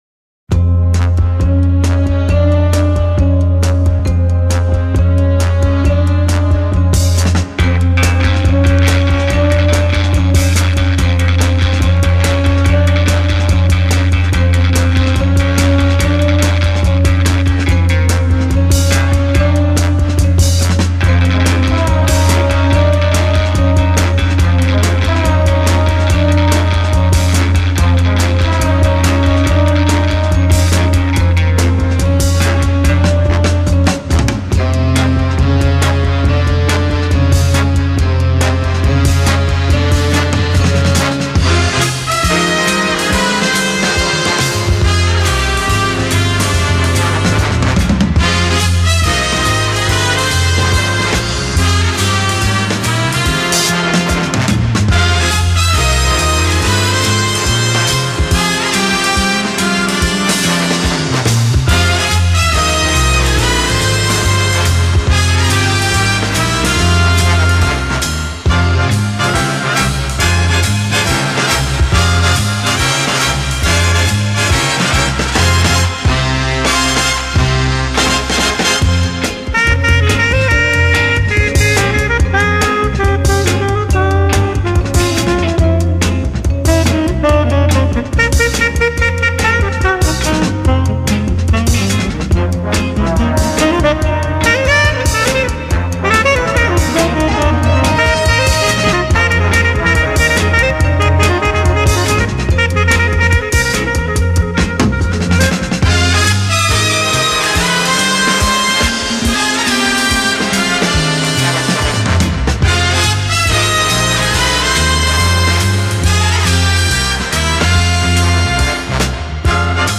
Genre: Soundtracks, movie scores